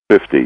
add missing GPWS and apdisc sounds